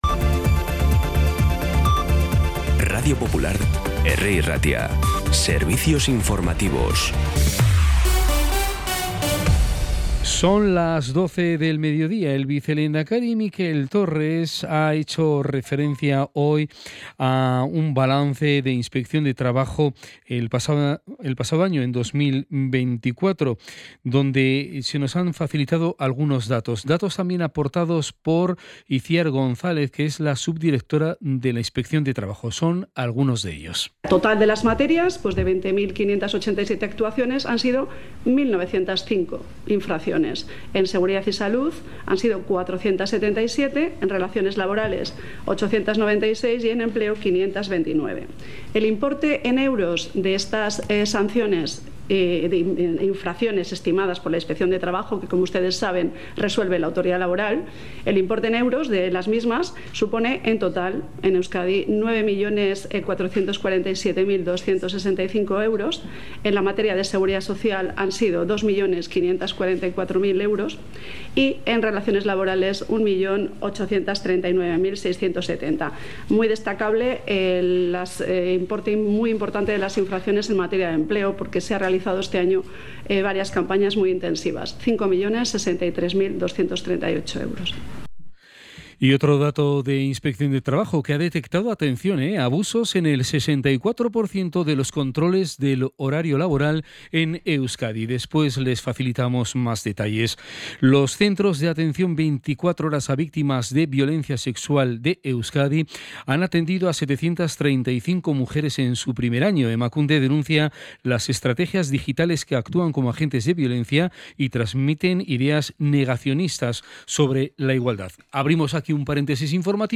Las noticias de Bilbao y Bizkaia del 19 de febrero a las 12
Los titulares actualizados con las voces del día.